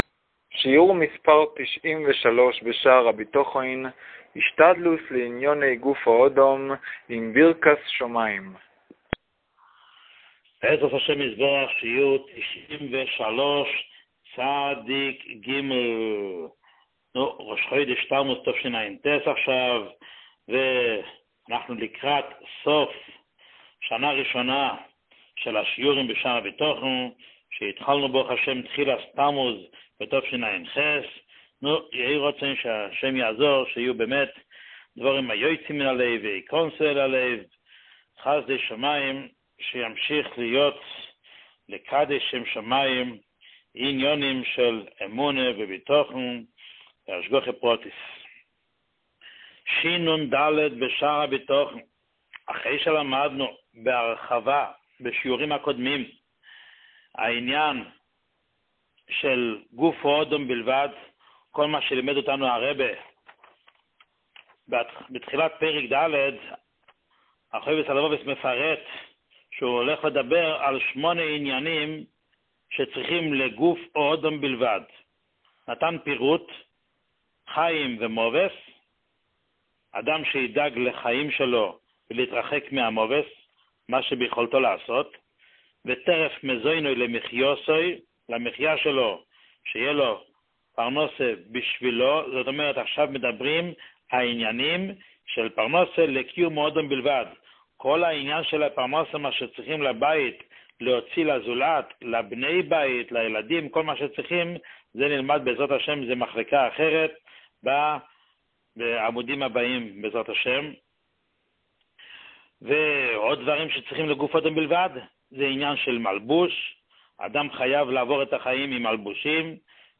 שיעור 93